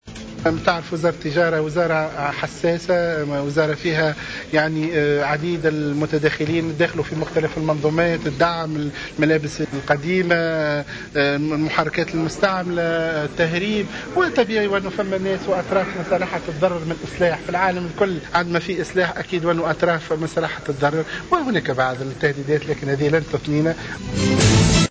أكد وزير التجارة، محسن حسن اليوم في تصريح لمراسل "الجوهرة أف أم" اليوم على هامش زيارته لولاية المهدية تلقيه تهديدات من قبل عدد من المهربين.